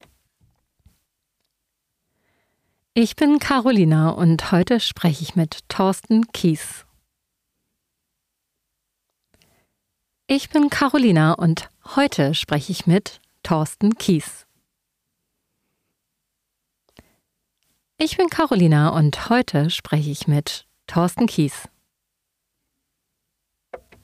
Widerspruch sind Ein Gespräch über Haltung, Verantwortung – und den